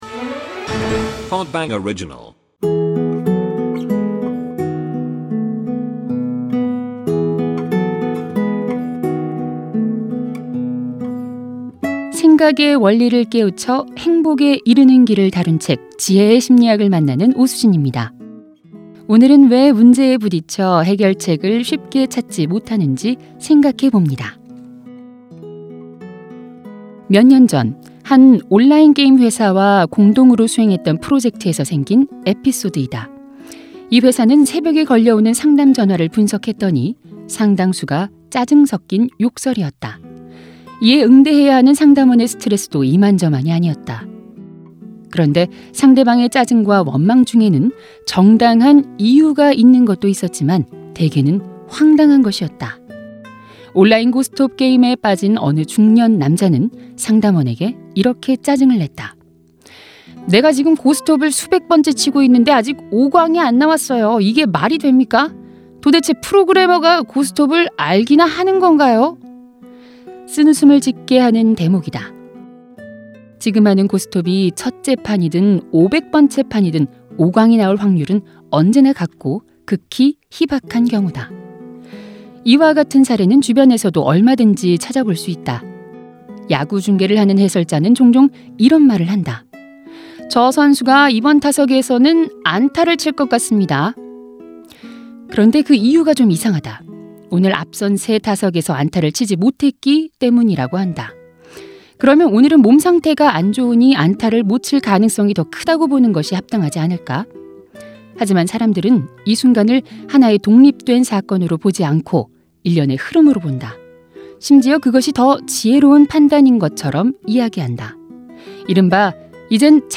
매거진 책 듣는 5분 ㅣ 오디오북